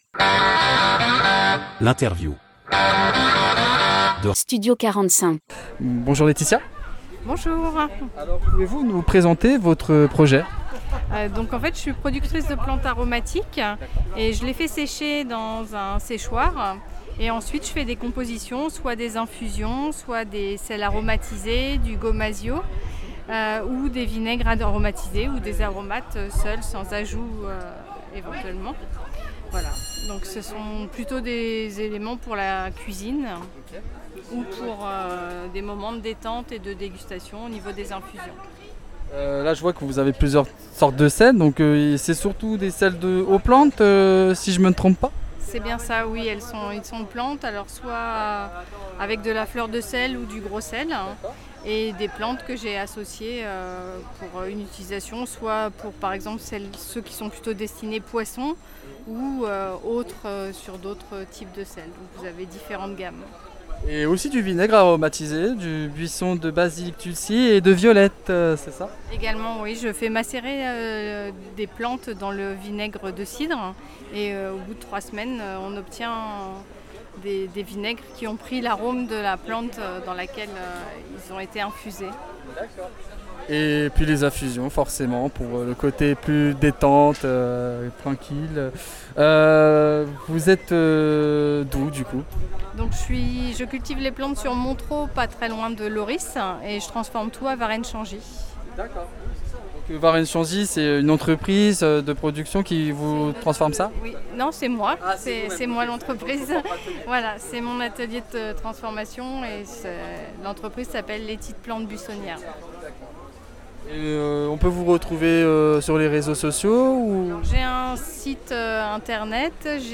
Interview de Studio 45